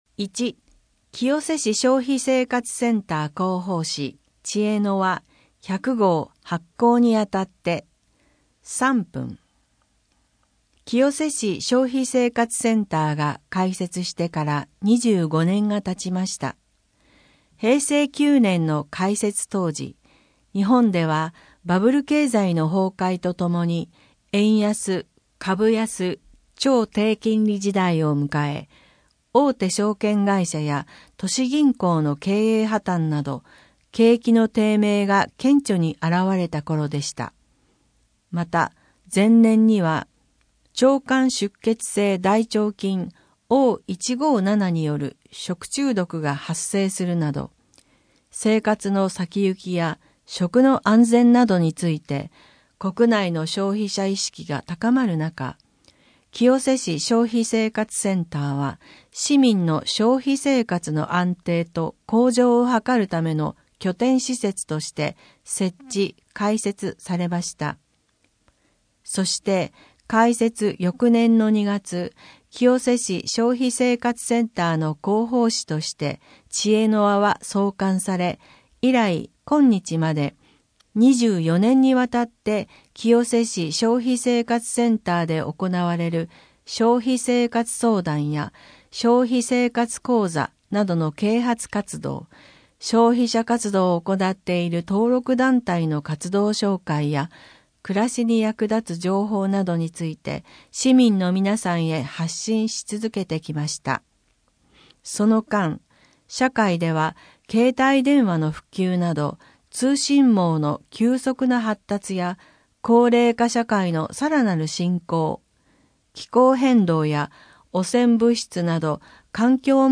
消費生活センターの主な業務 消費生活相談 消費生活に役立つ情報の提供 消費生活に関する講座の開催・啓発活動 リサイクル情報 施設について 4面 消費者活動の支援 消費生活センター広報誌「ちえのわ」100号 （PDF 2.3MB） 音声データ 声の広報は清瀬市公共刊行物音訳機関が制作しています。